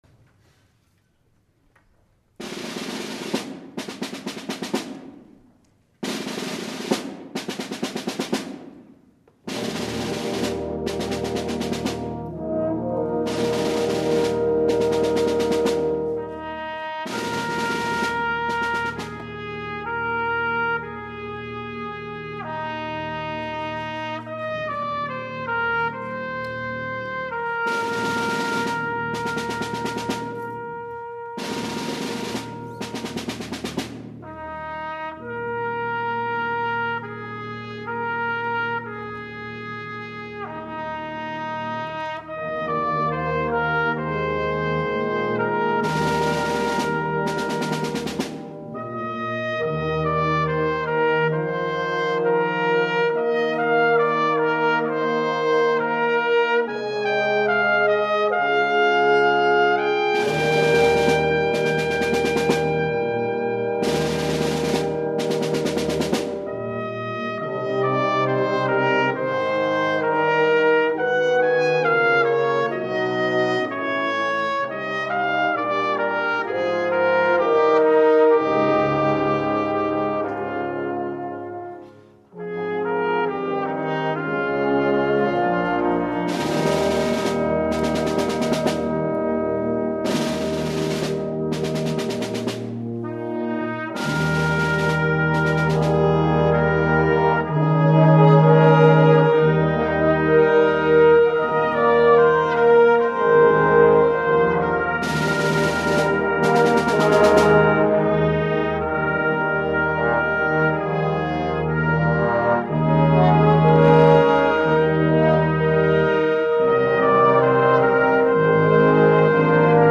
From Concert #2, May 5, 2011 MP3 files
Themes from JFK by John Williams.  Brass Ensemble